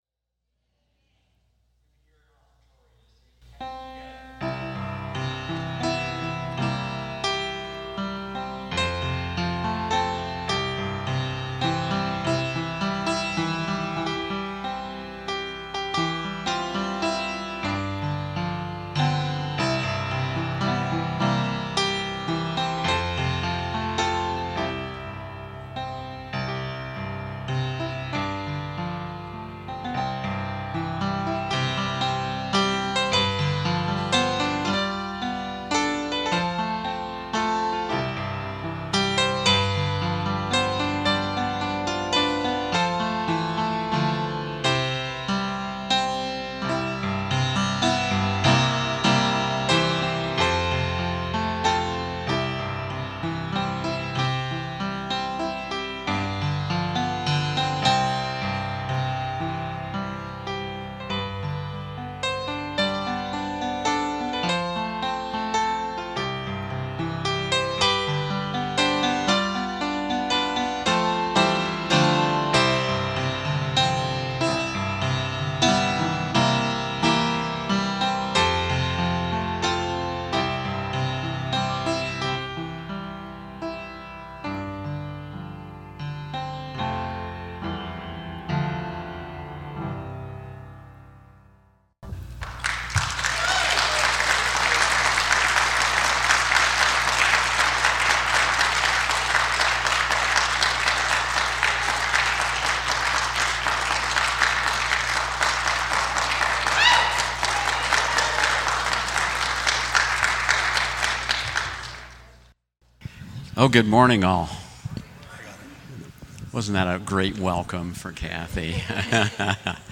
Weekly Sermons - Evangelical Free Church of Windsor, CO